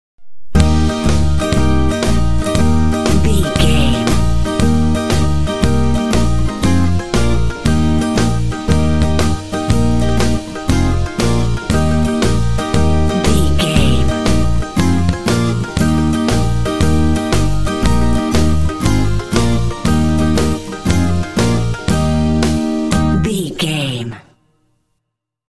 Uplifting
Ionian/Major
E♭
bouncy
cheerful/happy
joyful
bass guitar
acoustic guitar
drums
banjo
country